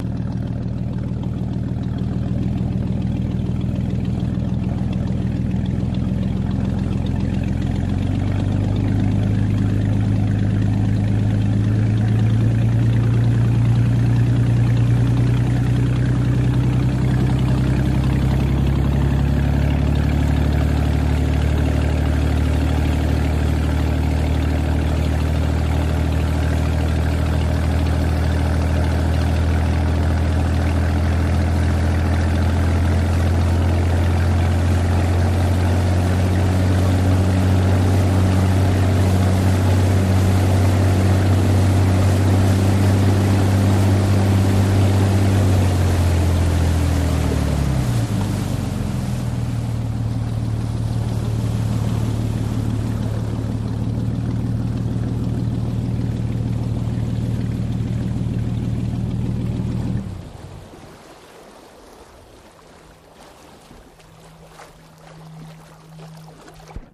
Boat Engine
V6 Chris Craft On Board, Start, Constant